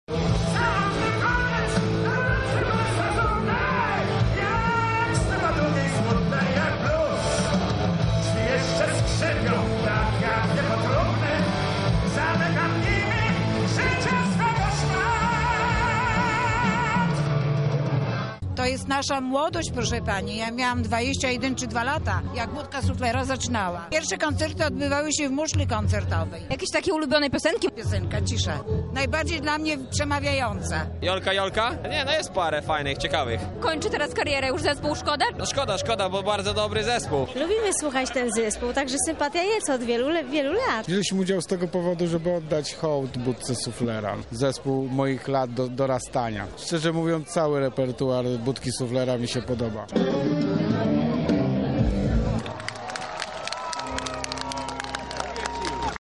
W czwartek na Placu Zamkowym zespół po raz ostatni zagrał dla mieszkańców Lublina.
Sprawdziliśmy jak podczas ostatniego koncertu bawili się fani zespołu.
Budka Suflera – koncert pożegnalny